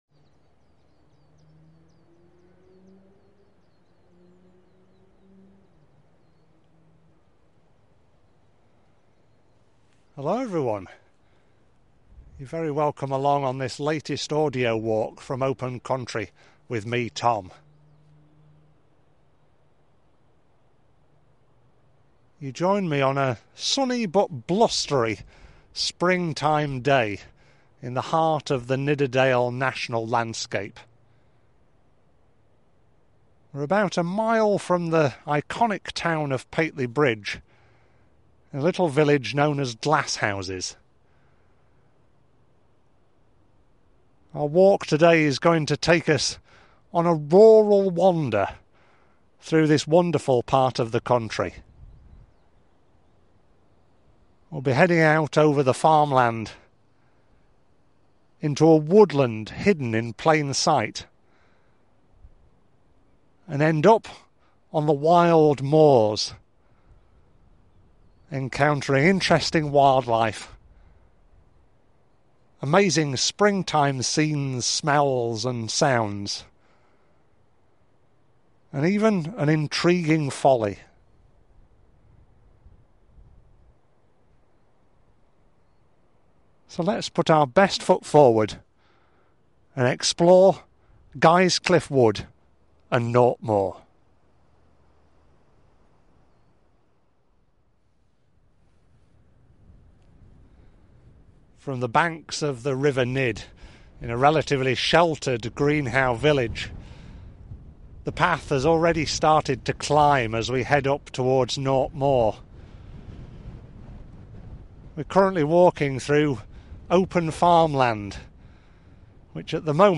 This audio trail accompanies: Yorke’s Folly
Guisecliff-Wood-Nought-Moor-Audio-Walk.mp3